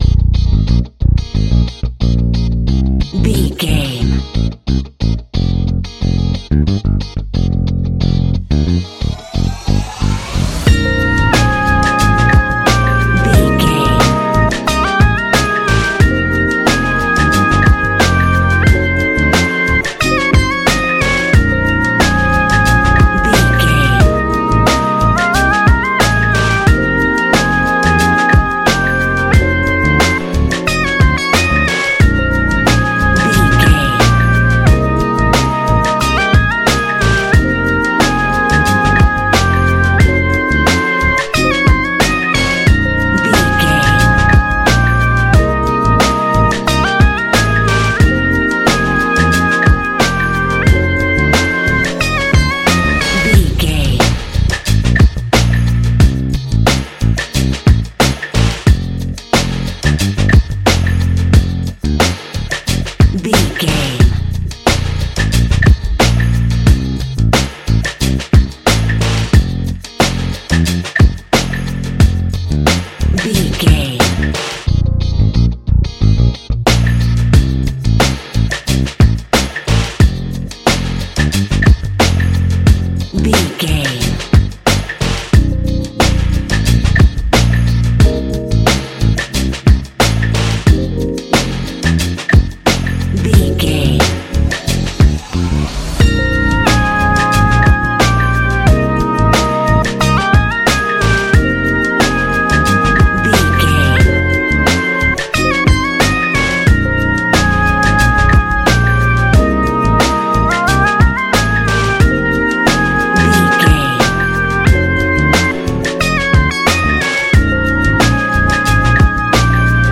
Ionian/Major
A♯
laid back
Lounge
sparse
new age
chilled electronica
atmospheric
instrumentals